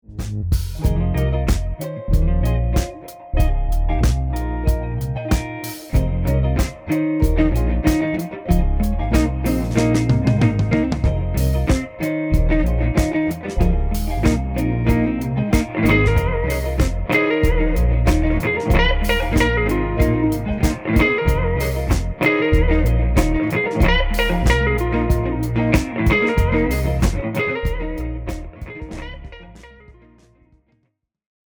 Tracking R&B Guitars with sE
Incredibly open and uncompressed, every nuance of the instrument is represented.
I used my favorite close-mic combination, the VR1 passive ribbon microphone and the T1 large-diaphragm condenser. The VR1’s round, warm tone perfectly combines with the fast transients and clarity of the T1 to recreate the full color palette of the amplifier. An added bit of fun here is that I’m playing through a 2×12” cabinet with mismatched speakers, creating a nice color difference, and making each microphone channel more distinctive. I also mixed in a T2 large-diaphragm condenser in omnidirectional mode